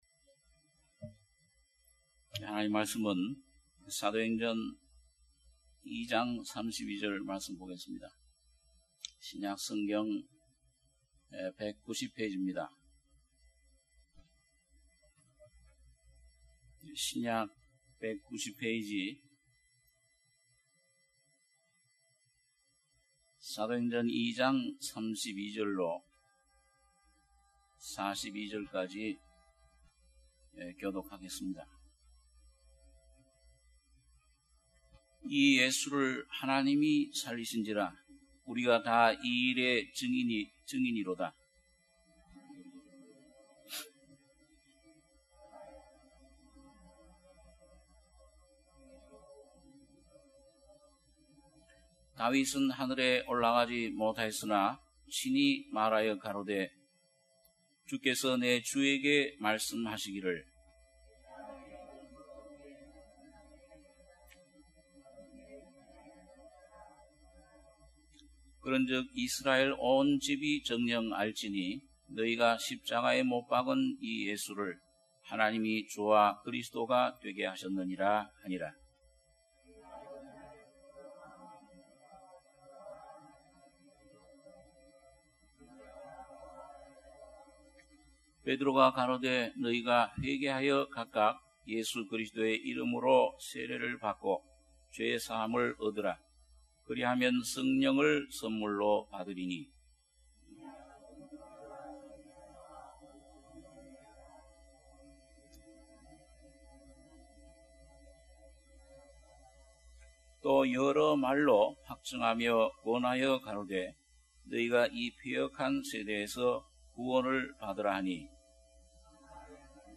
주일예배 - 사도행전 2장 32-42절